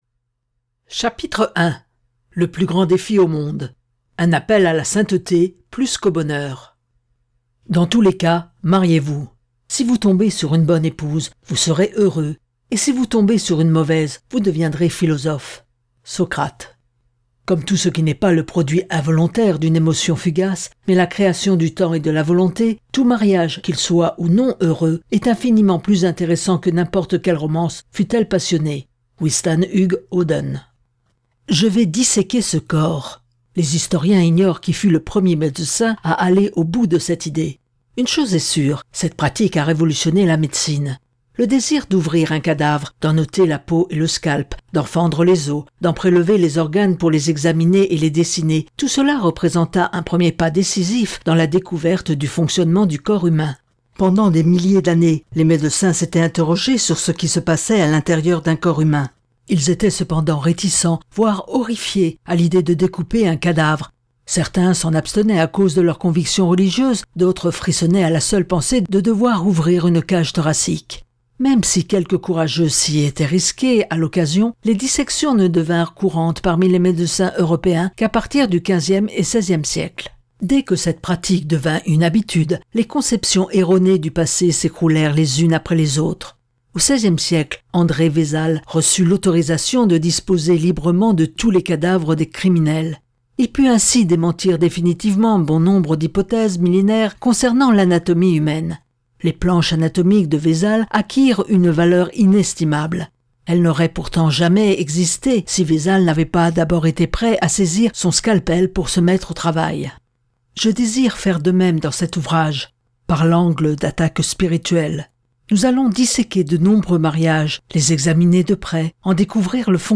Lire un extrait - Vous avez dit Oui à quoi ? de Gary Thomas